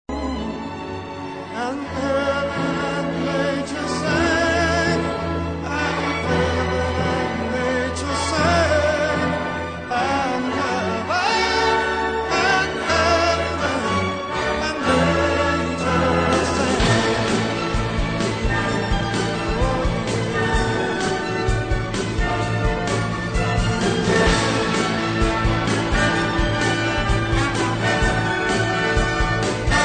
key: A-major